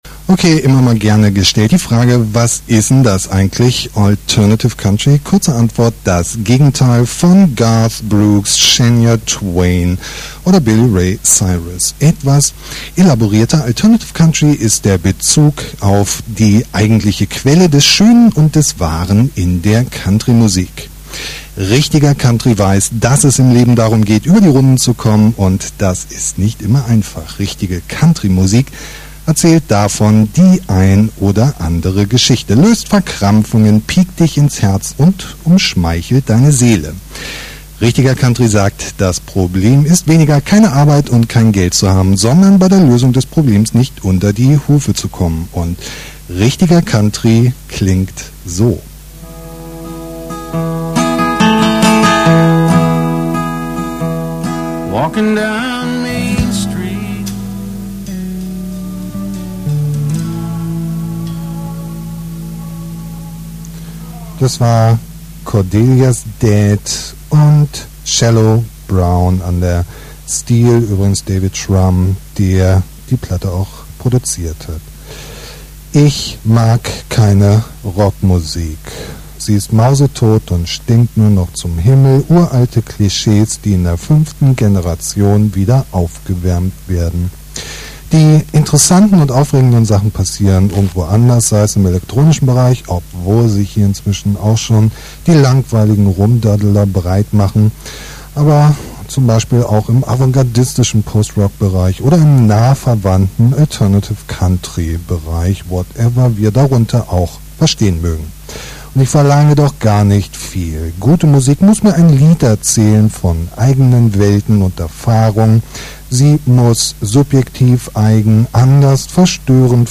Dear Listener, here's a mix of some live presentations from my show.